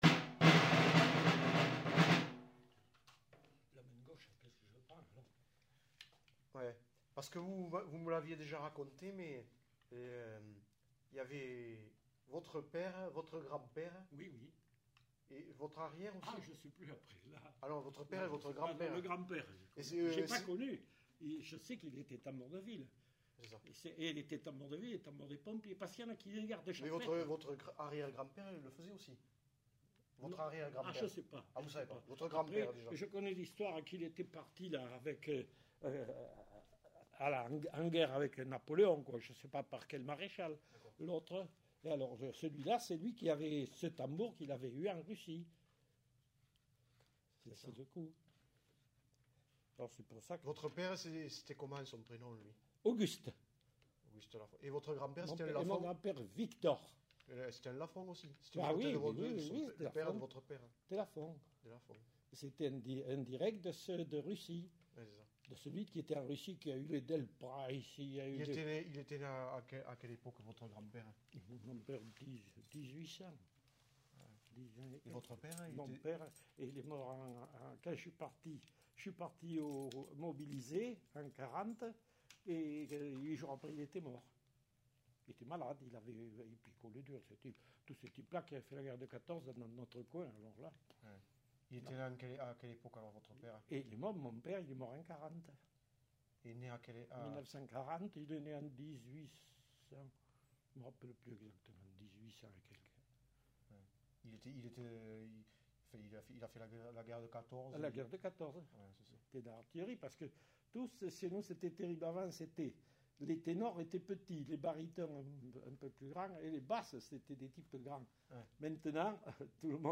Aire culturelle : Quercy
Genre : récit de vie